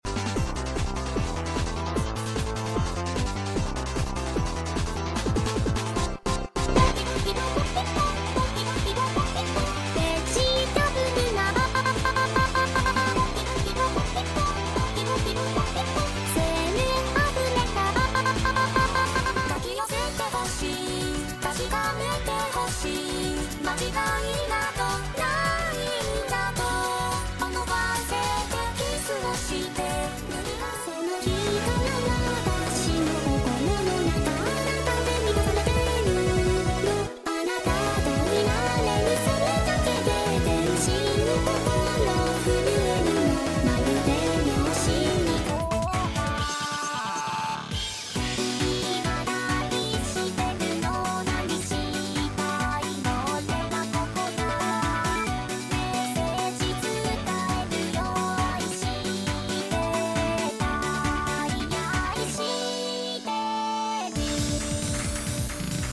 have a BPM of 150